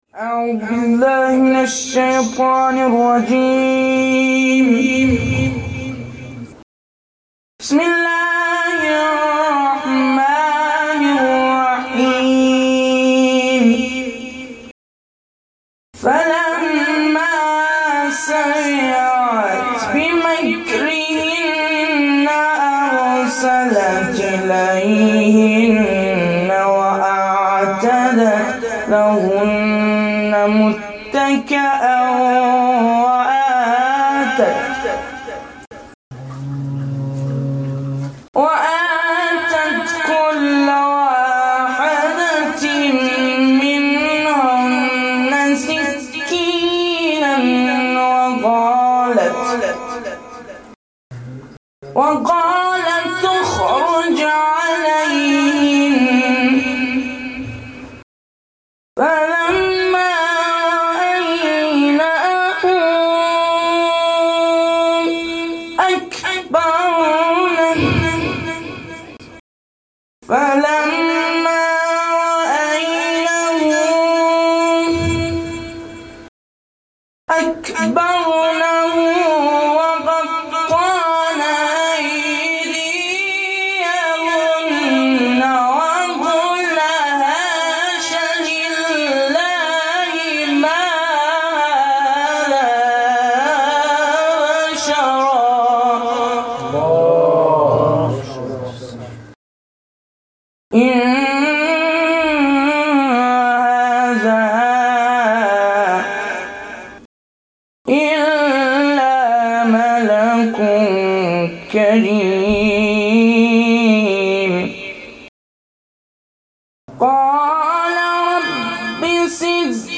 قرائت شب دوم فاطمیه 1393
هیئت رایت الهدی کمالشهر
مداحی فاطمیه